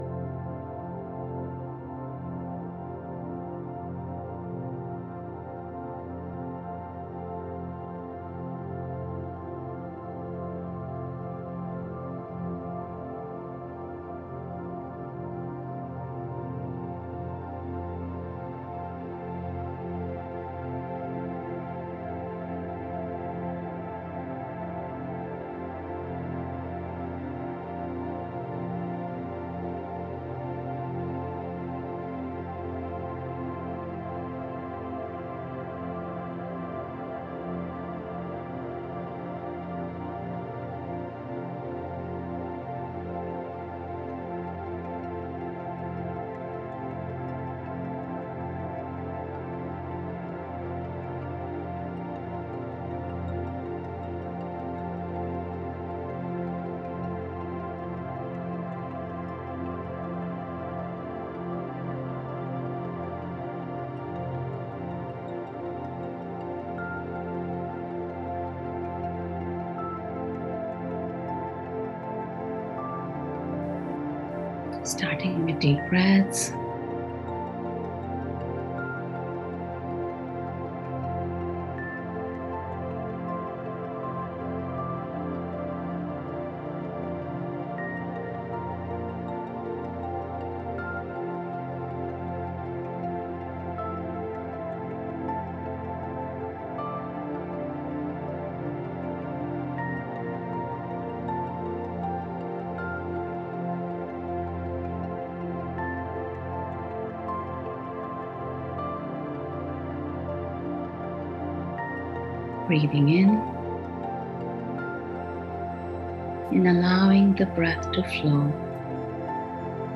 Click the button below to enjoy your 30-minute meditation
qkMAjvE1RtWTalj8JPYc_Inner_Self_Meditation.mp3